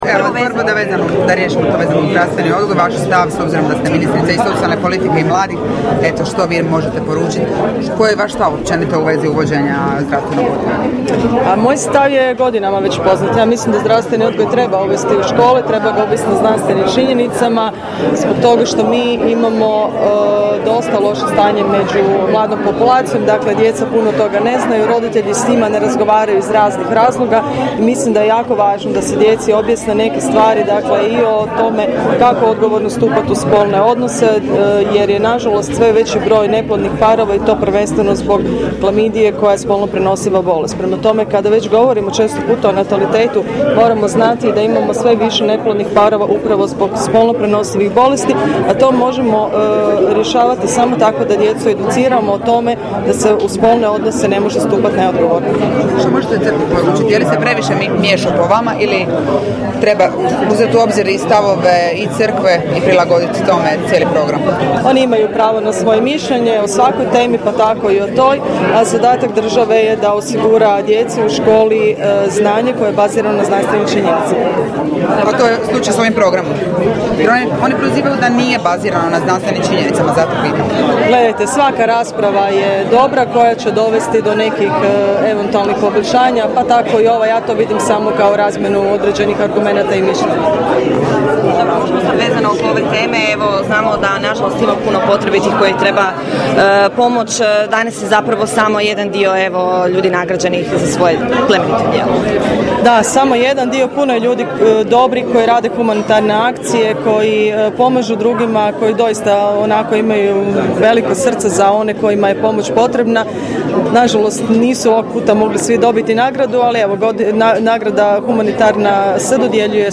izjava potpredsjednice Vlade i ministrice socijalne politike i mladih Milanke Opačić nakon svečanosti dodjele nagrada